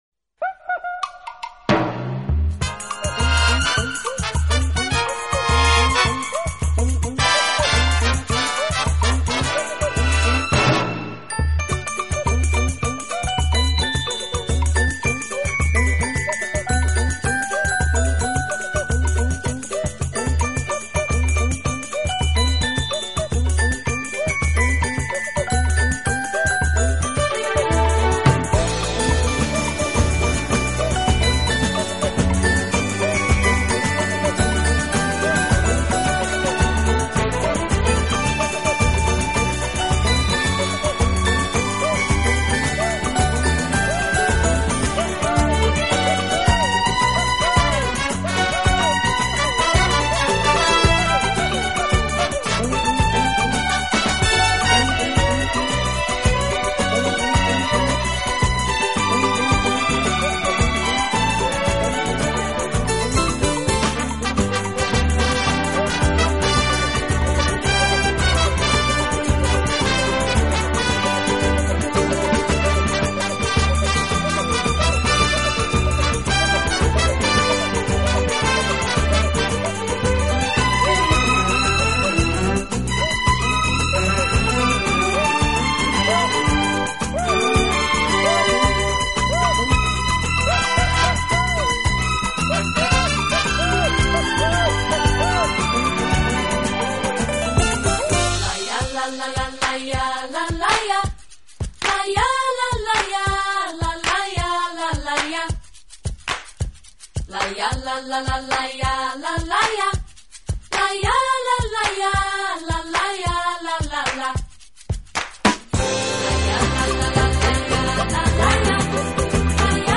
【顶级轻音乐】
浪漫而不轻浮，粗旷之中带着细腻，热情之中蕴藏着宁静。